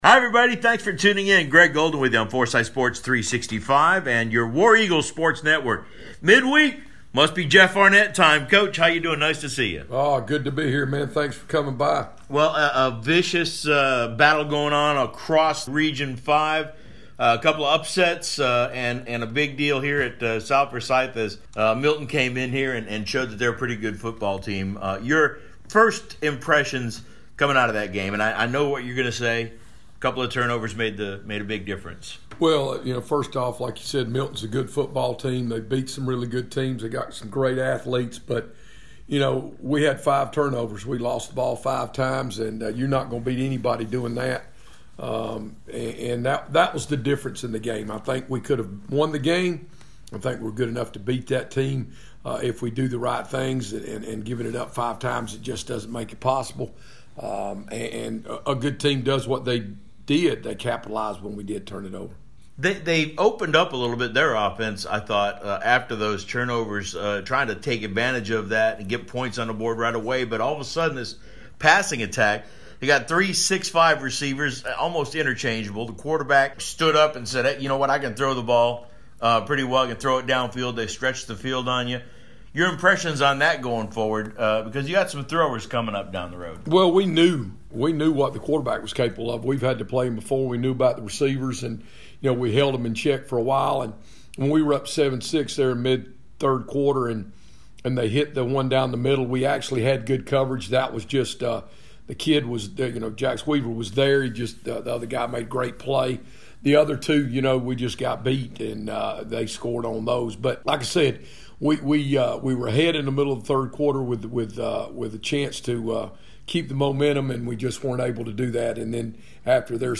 mid-week interview